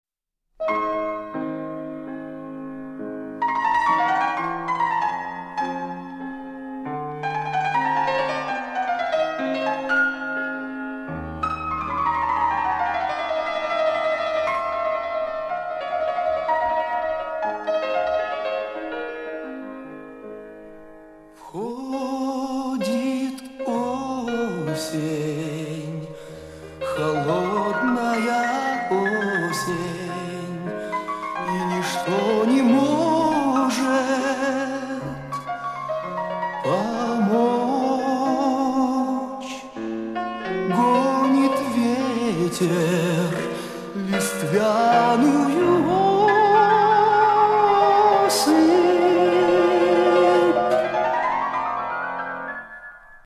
Ох,ребятушки-ребятушки(С),совсем я что-то устал от глухого звука,который получается после экспорта песни в mp3 в программе Reason.